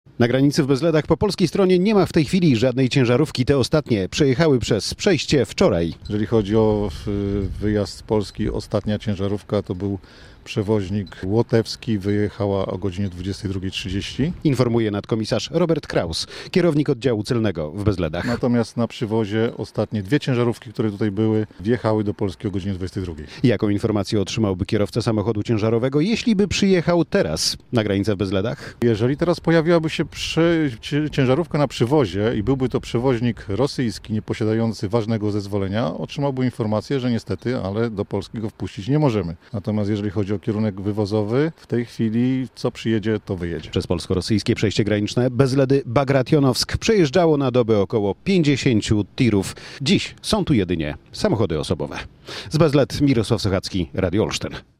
Bezledy